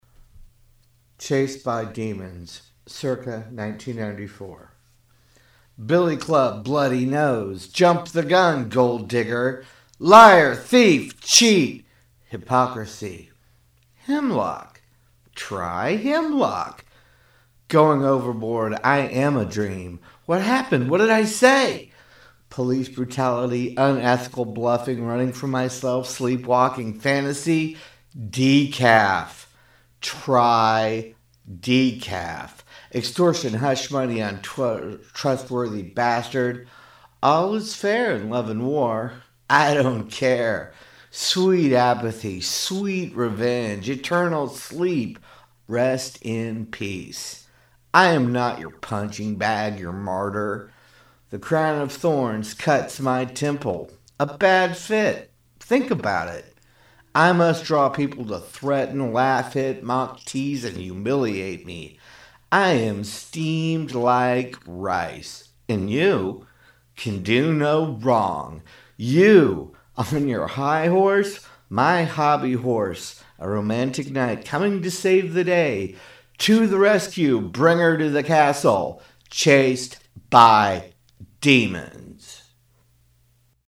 Categories: Manic Beatnik Riffing